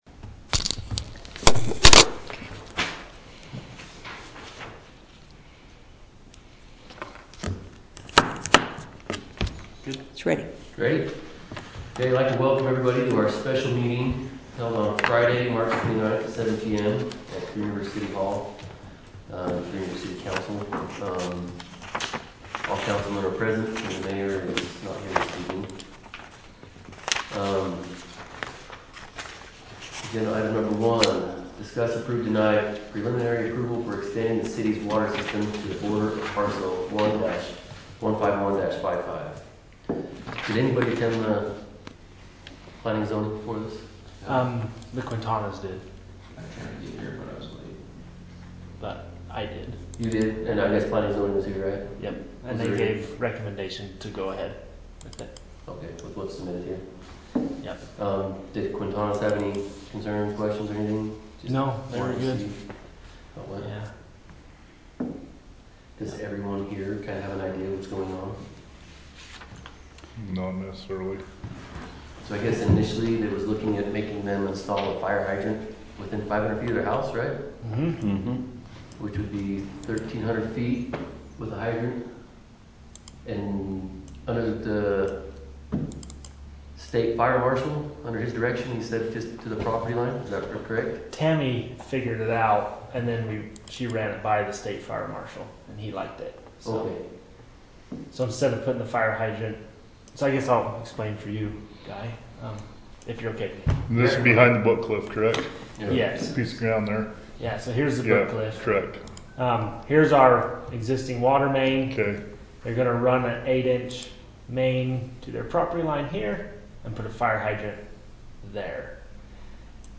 Special Meeting
City Council